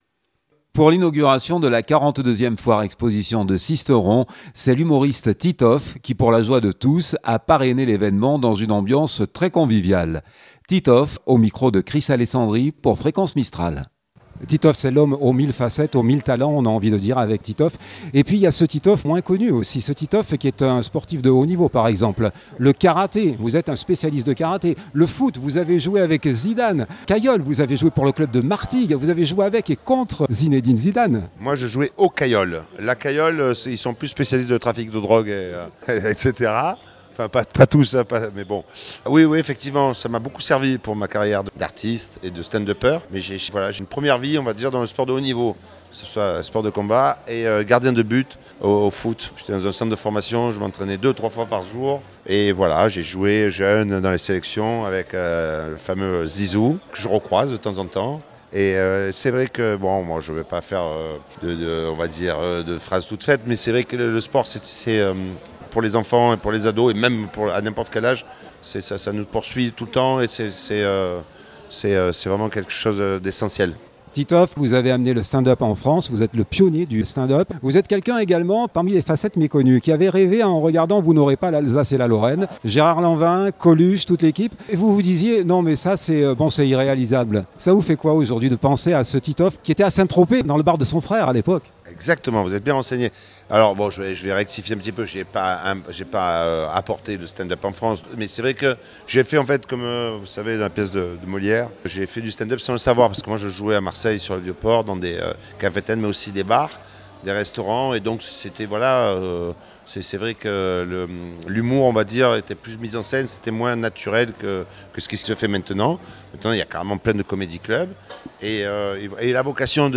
Pour l’inauguration de la 42ème Foire Exposition de Sisteron, c’est l’ humoriste et comédien Titoff qui pour la joie de tous à parrainé l’ évènement dans une ambiance bien conviviale.